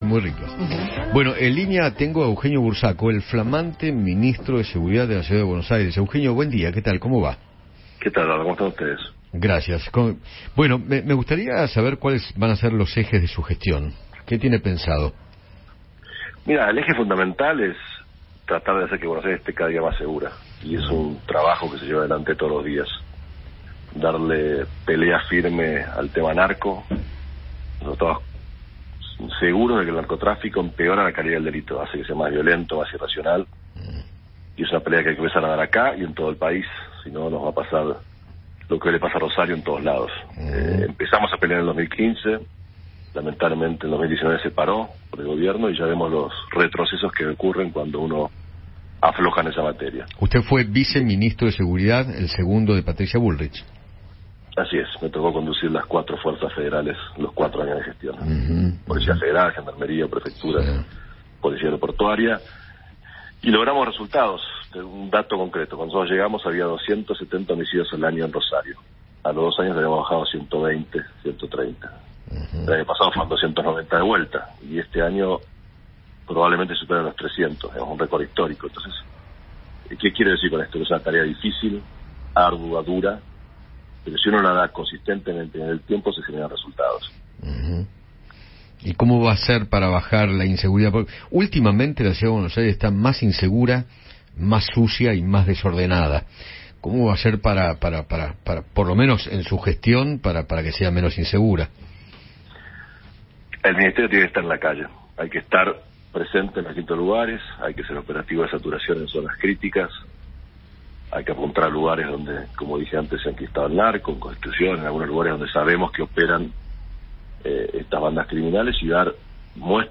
Eugenio Burzaco, flamante Ministro de Justicia y Seguridad de la Ciudad de Buenos Aires, conversó con Eduardo Feinmann sobre los ejes de su gestión y señaló que “el Ministerio tiene que estar en la calle”.